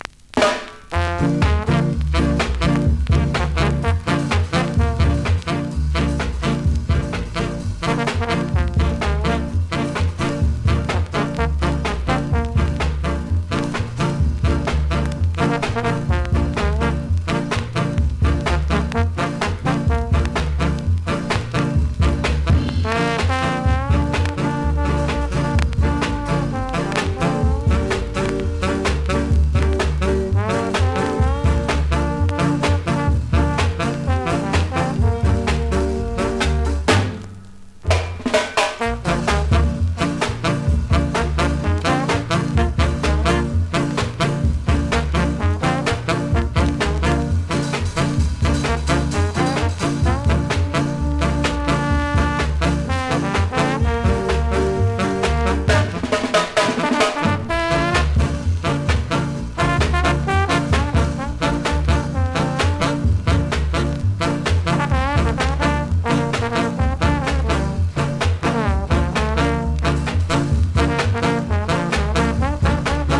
プレイはOKです)   コメントキラーSKA INST!!
スリキズ、ノイズそこそこありますが